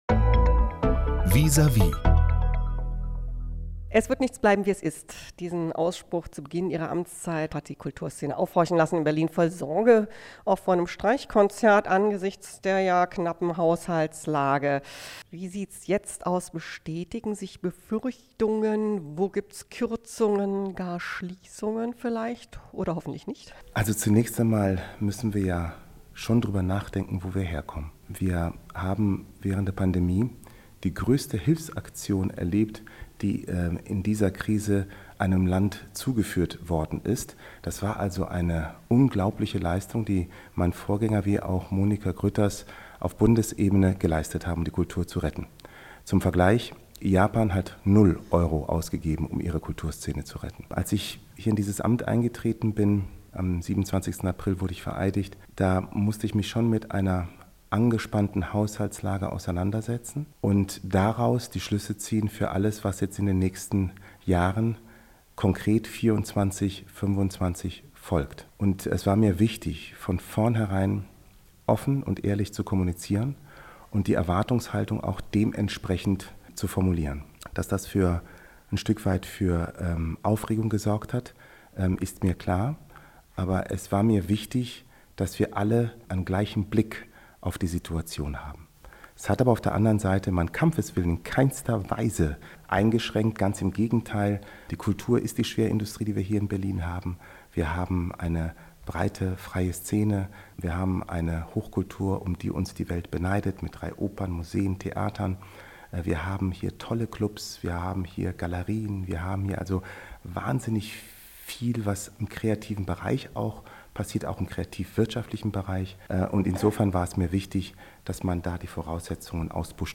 Neuer Kultursenator Joe Chialo im Interview: Kulturetat über eine Milliarde Euro | rbb24 Inforadio